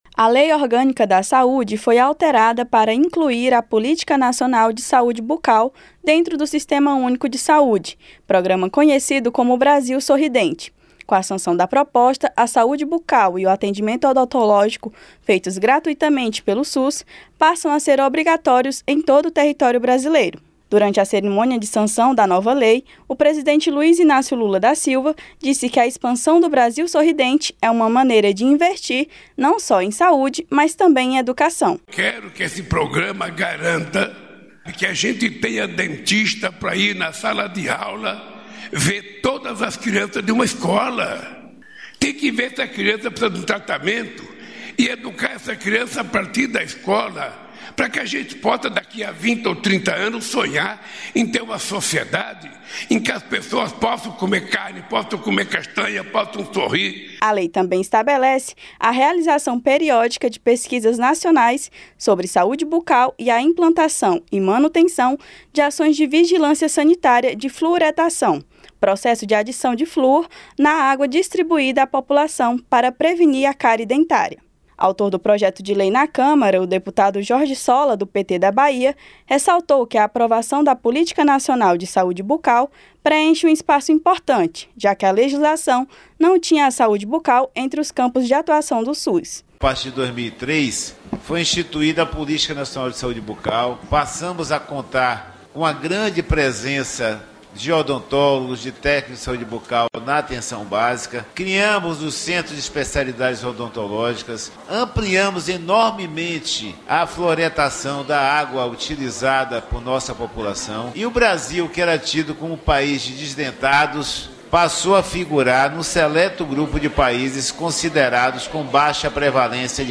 SANCIONADA A LEI QUE ACRESCENTA A SAÚDE BUCAL AO SISTEMA ÚNICO DE SAÚDE, TORNANDO O PROGRAMA BRASIL SORRIDENTE FERRAMENTA NACIONAL. A REPÓRTER